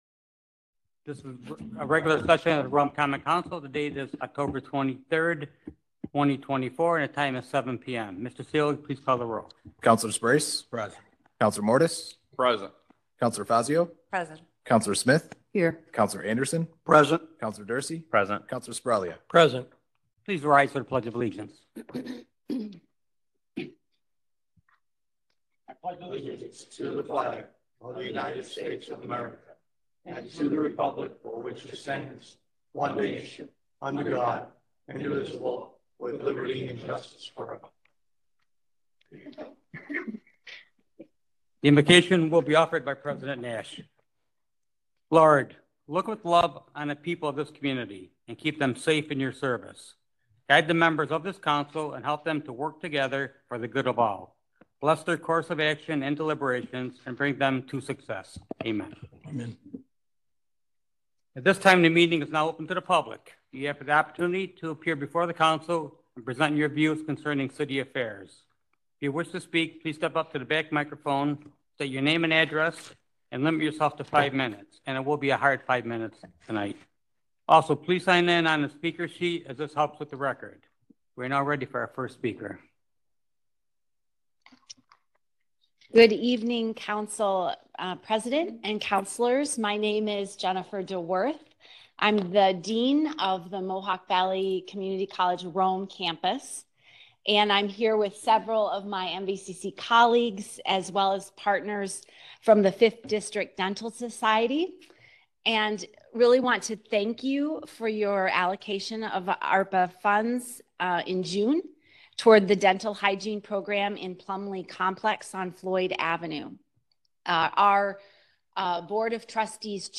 Audio file from October 23, 2024 Meeting